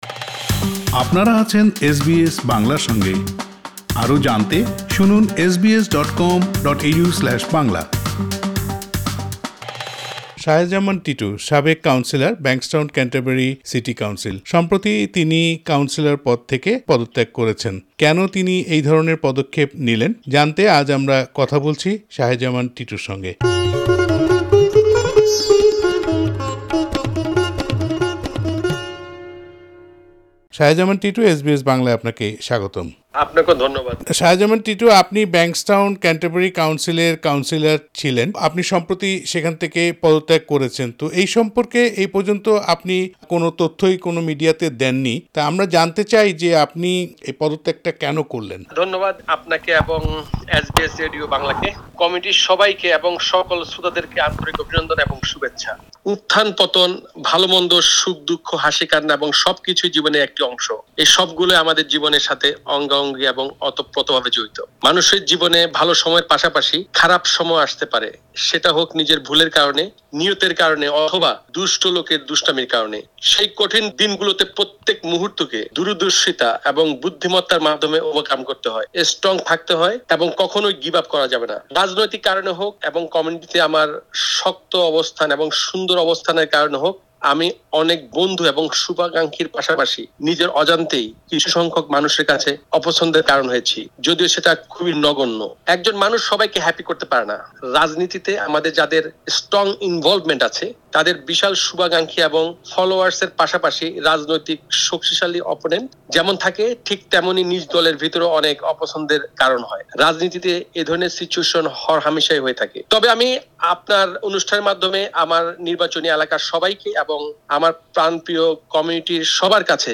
কেন তিনি পদত্যাগ করলেন এ নিয়ে কথা বলেছেন এস বি এস বাংলার সঙ্গে।সাক্ষাৎকারটি শুনতে উপরের অডিও প্লেয়ারের লিংকটিতে ক্লিক করুন।